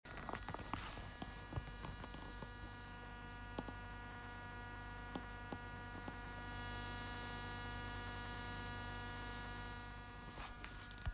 Apogee Quartet rauscht
Das Rauschen kommt vom Sub!!!
Dann schliesse ich zusätzlich Apogee in iMac an, es rauscht wieder und zwar permanent und mit gleicher Lautstärke unabhängig vom Pegel was rauskommt.